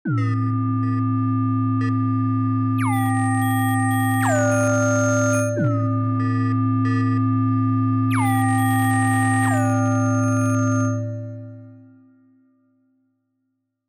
Some more random generated drones, is going to pair very well with Lyra 8